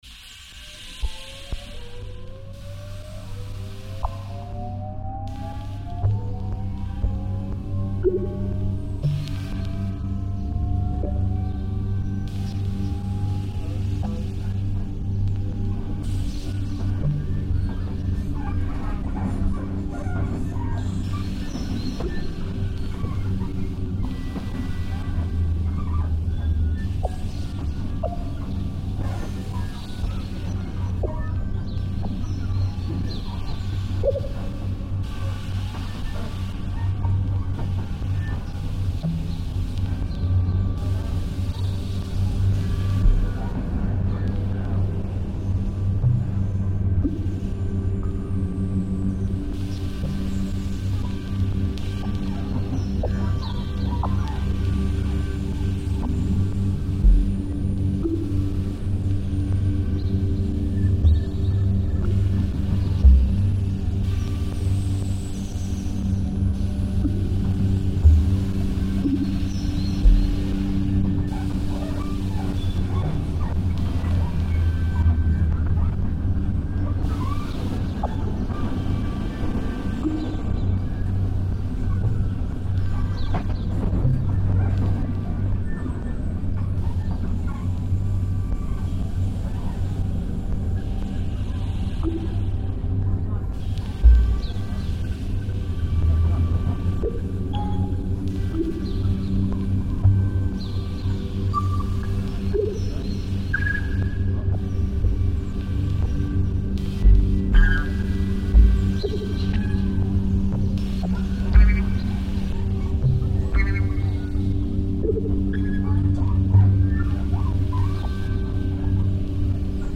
Paris alarm test reimagined